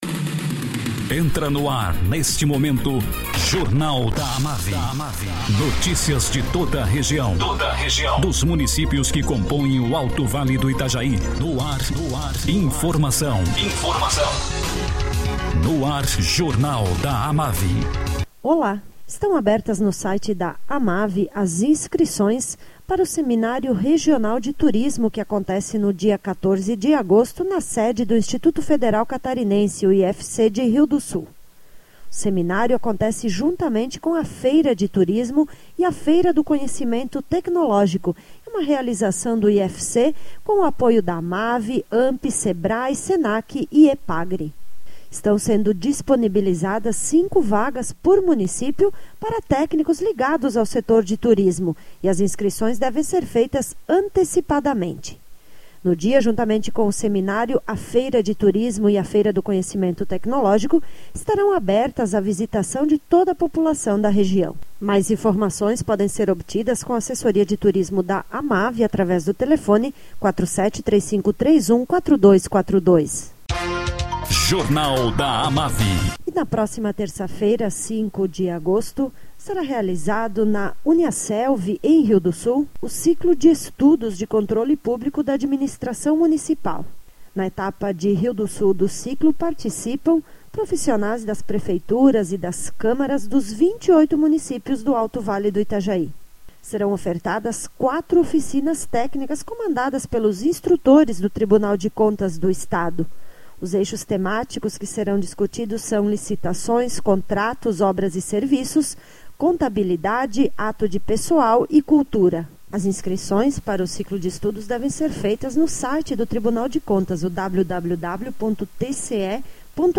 Presidente da AMAVI, prefeito José Constante, fala sobre palestra que acontece amanhã no IMA em Rio do Sul, fazendo parte do ciclo realizado pela AMAVI em comemoração ao cinquentenário.